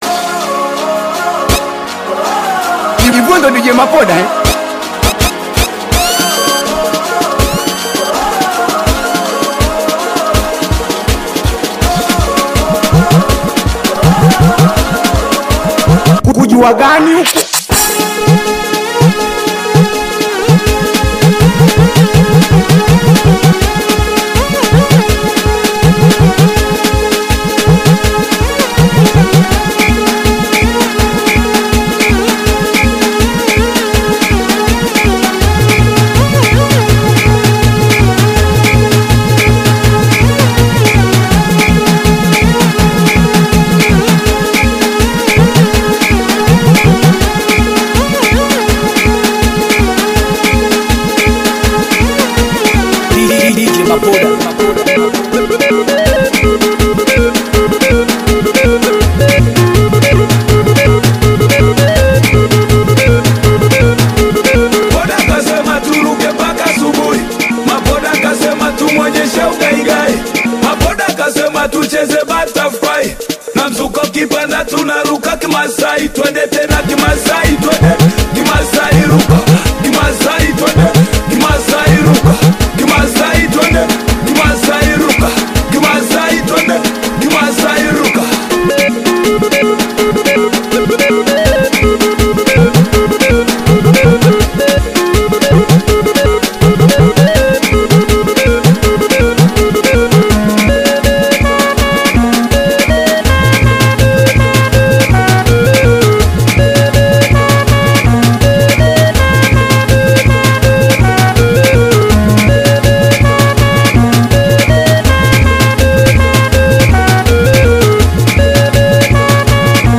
Singeli music track
Tanzanian Bongo Flava Singeli
singeli beat song